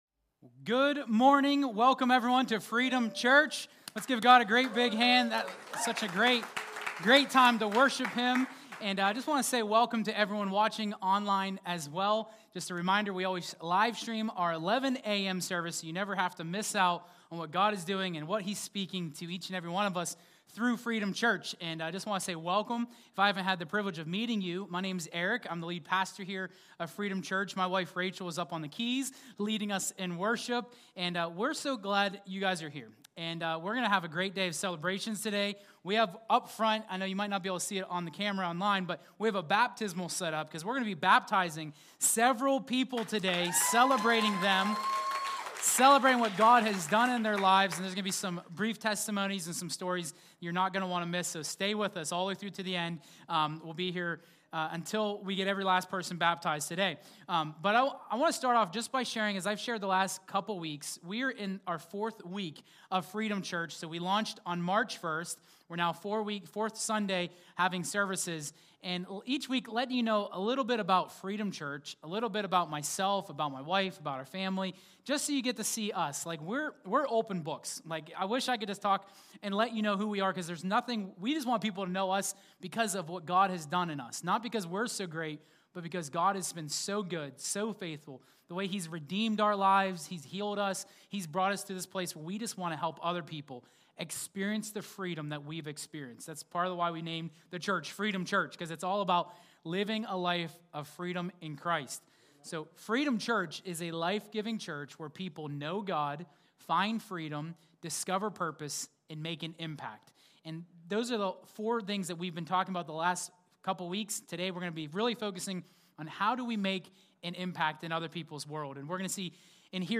2026 Freedom Church Launch Sunday Service Explore the core message of freedom in Christ at Freedom Church